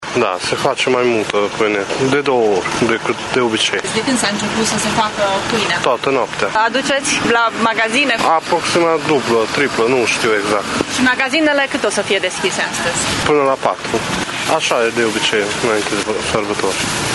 Cele mai mari cozi erau la magazinele de pâine. În consecință, și fabricile s-au pregătit cu o cantitate triplă de pâine, astfel că toată noaptea au ars cupoarele, spune reprezentantul unei brutării din Tîrgu-Mureș: